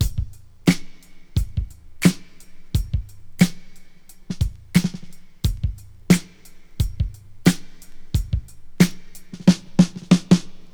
• 89 Bpm 00's Drum Groove E Key.wav
Free breakbeat sample - kick tuned to the E note. Loudest frequency: 943Hz
89-bpm-00s-drum-groove-e-key-Kgd.wav